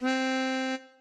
melodica_c.ogg